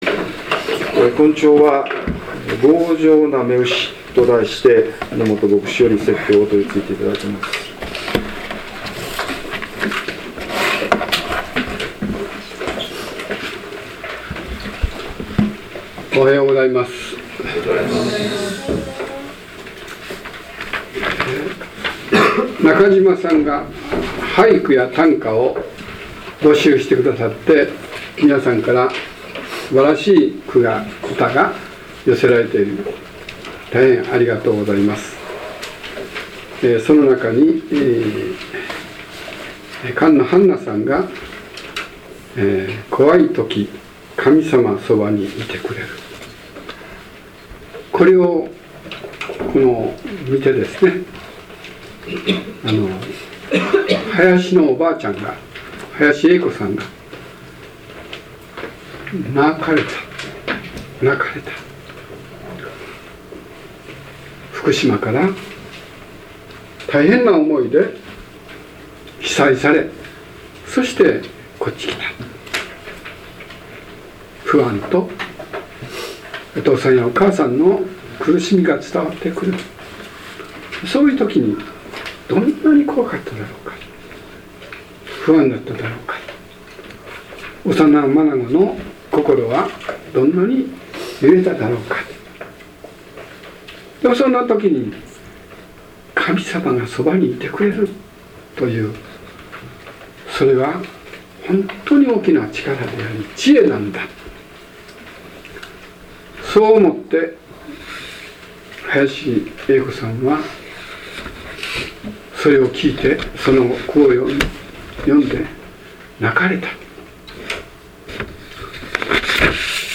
説教要旨 2013年10月27日 強情な雌牛 | 日本基督教団 世光教会 京都市伏見区